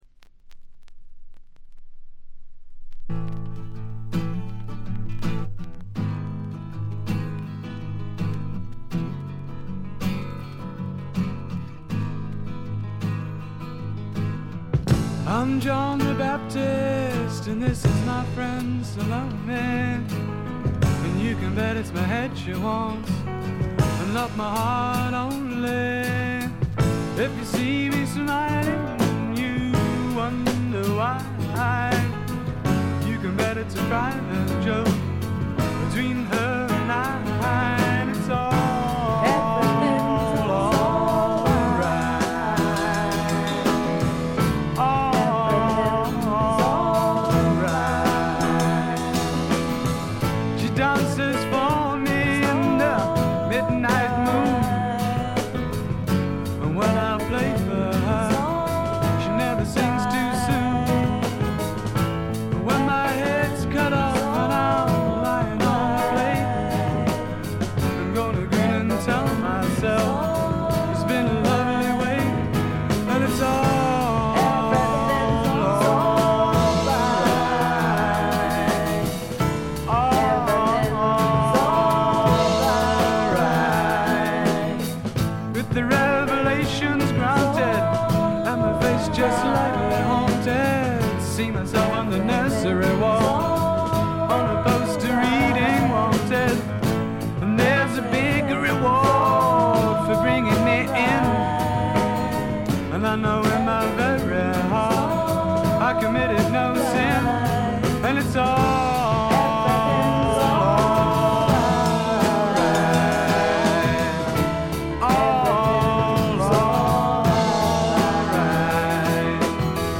ごくわずかなノイズ感のみ。
音の方はウッドストック・サウンドに英国的な香りが漂ってくるという、この筋の方にはたまらないものに仕上がっています。
試聴曲は現品からの取り込み音源です。